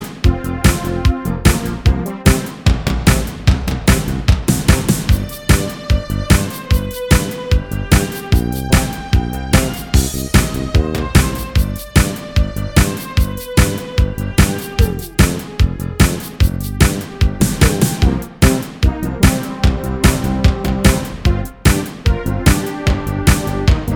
Minus Guitars Pop (1980s) 3:35 Buy £1.50